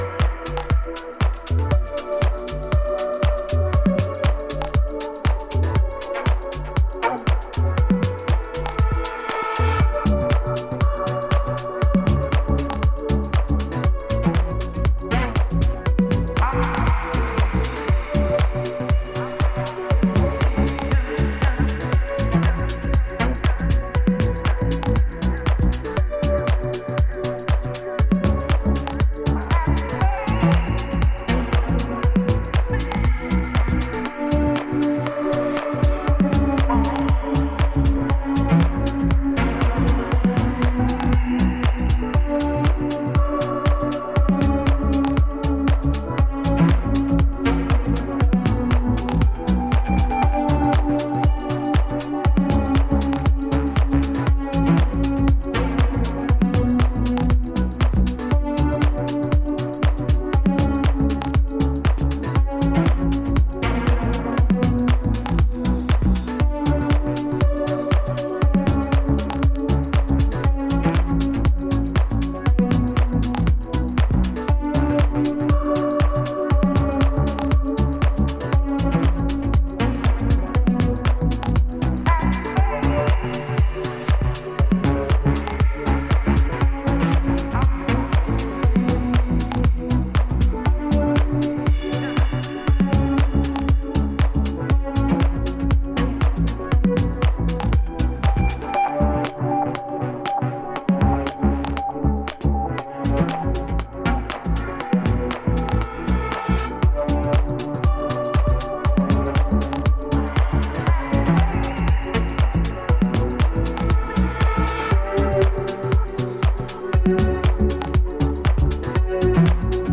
ノスタルジックなコードが印象的なミニマル・ハウス